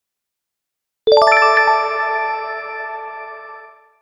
Correct Sound Effect Soundboard: Play Instant Sound Effect Button